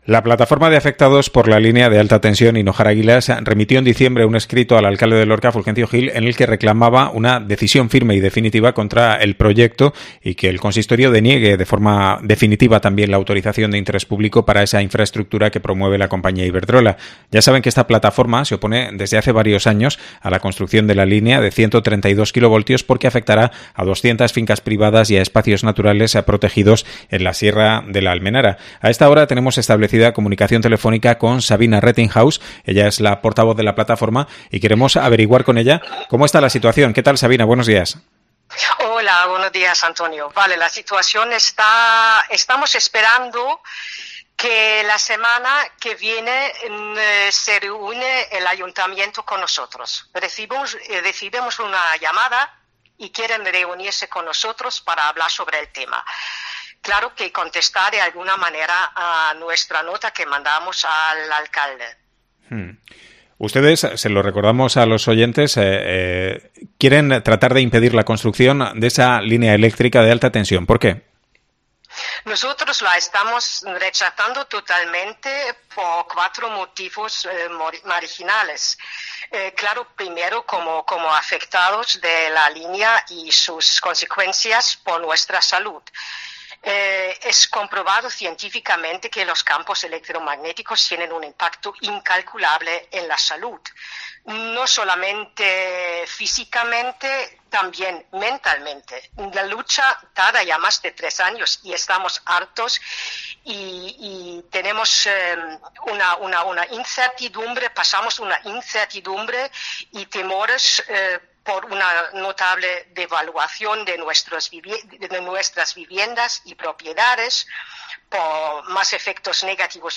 MEDIO AMBIENTE